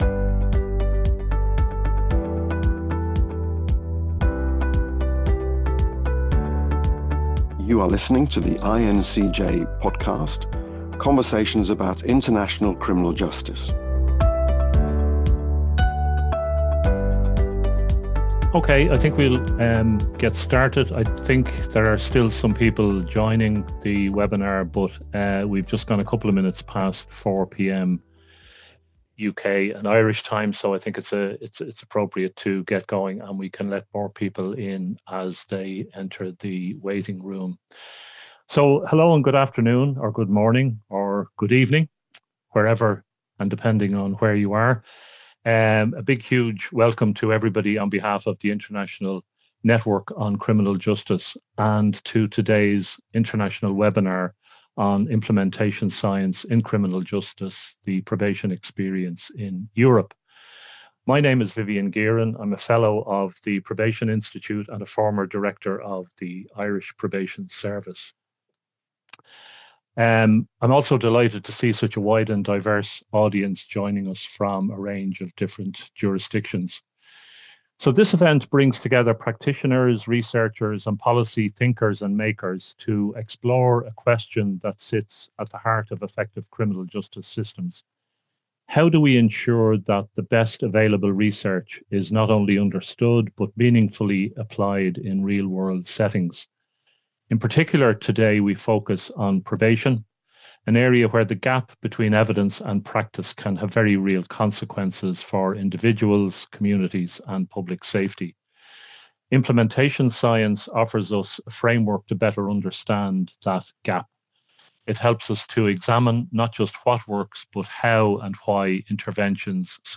The first IN-CJ webinar on implementation science addresses a persistent challenge in criminal justice practice. How do services move from knowing what works to embedding those approaches in everyday professional activity?